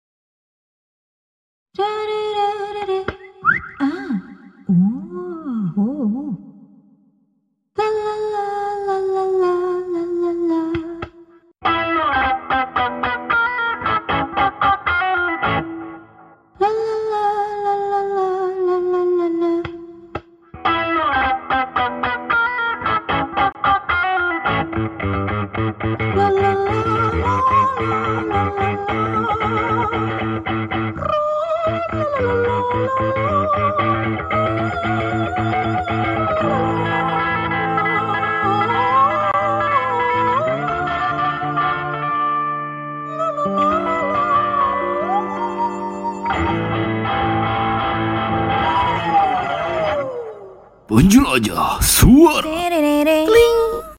Menjelajah Suara dari Serial Animasi sound effects free download